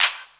35CLAP01  -L.wav